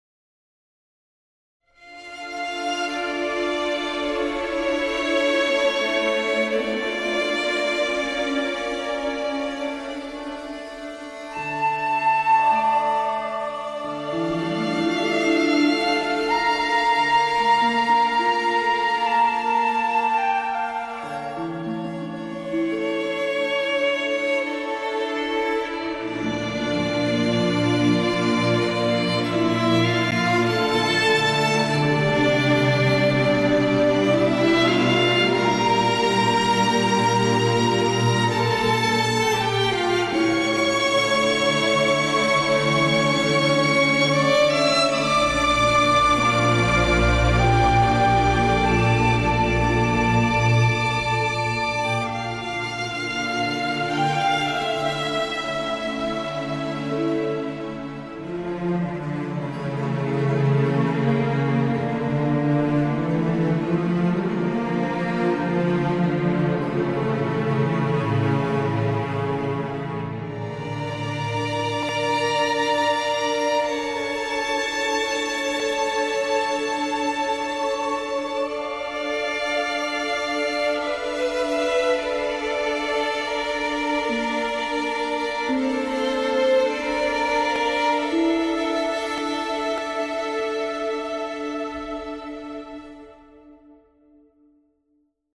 It's like fantasy epic orchestral, starts with a violin in high notes.
music fantasy epic_0.mp3